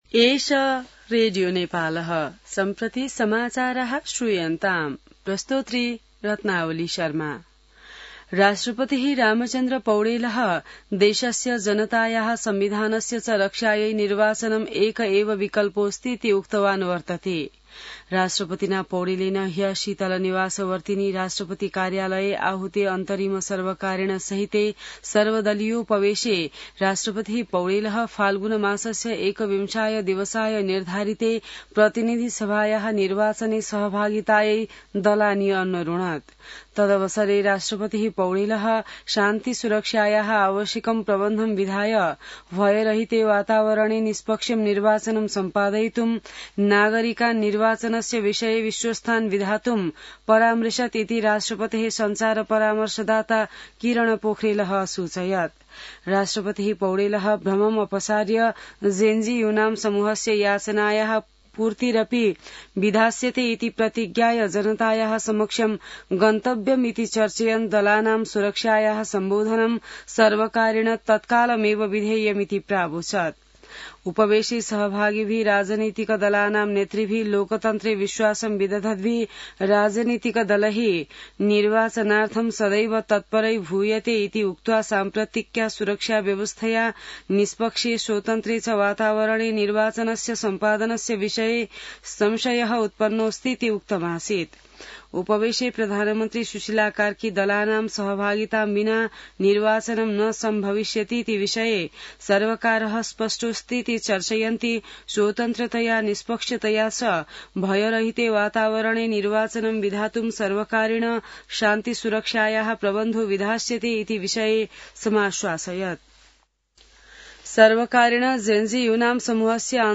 संस्कृत समाचार : २५ असोज , २०८२